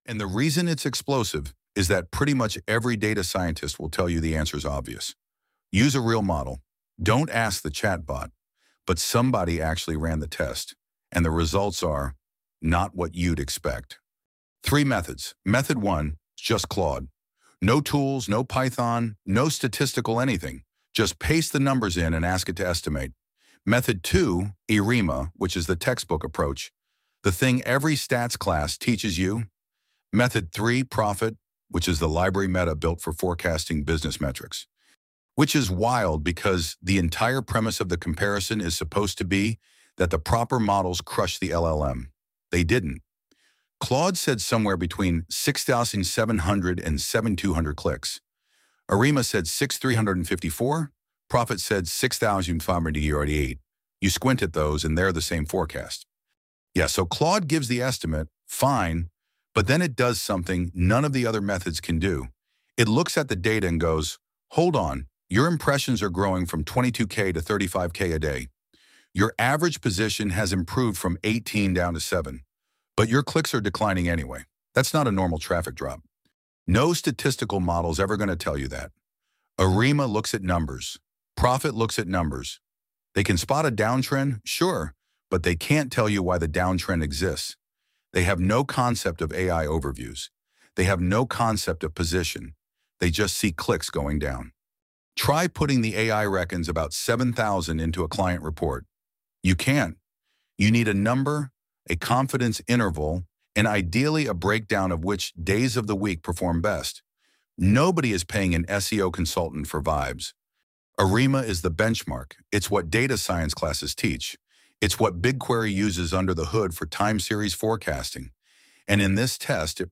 The voice quality is genuinely good. It sounds like someone reading, not a robot. The pacing is fine. The script captures the actual argument without turning into a promo read.